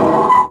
metal scratch.aiff